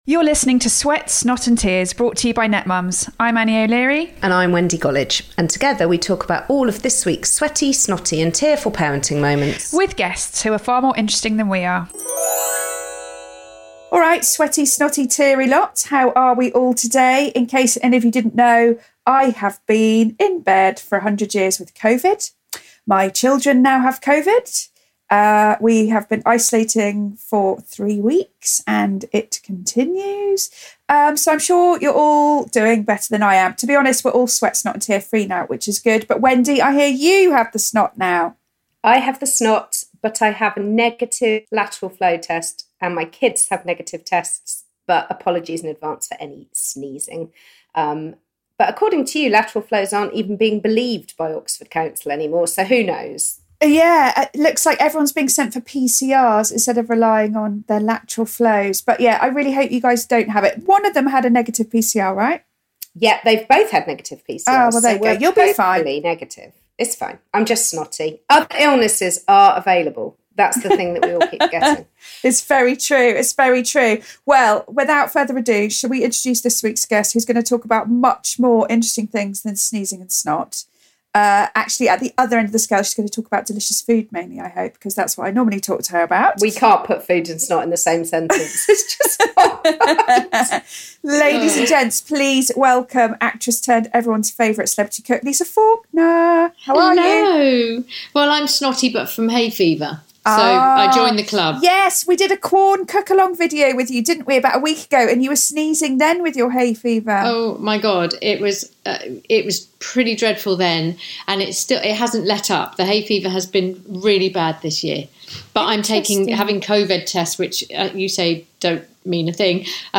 actress turned celeb cook, Lisa Faulkner. From the agonies of ectopic pregnancy to the stress of IVF; that first day as an adoptive mum to her golden rule as a step-mum: NEVER parent your partner's kids.